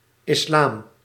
Ääntäminen
Synonyymit foi de Mahomet mahométisme Ääntäminen France: IPA: [is.lam] Haettu sana löytyi näillä lähdekielillä: ranska Käännös Ääninäyte Substantiivit 1. islam {m} Suku: m .